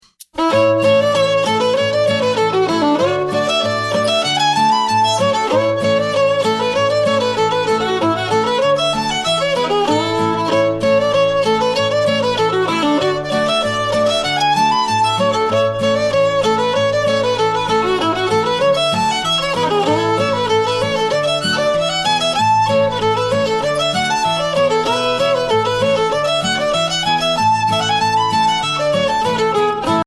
Voicing: Fiddle